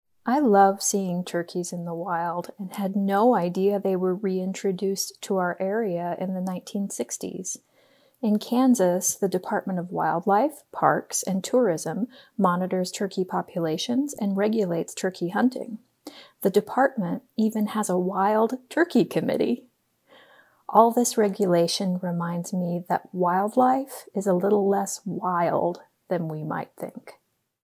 Listen to a curator talk about this work.